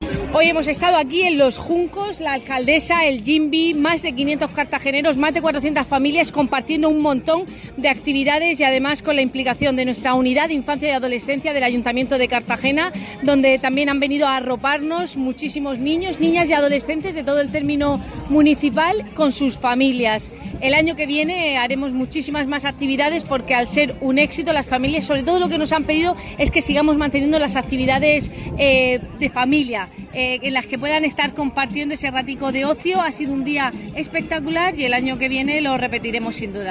Enlace a Declaraciones de Cristina Mora sobre el Día Internacional de las Familias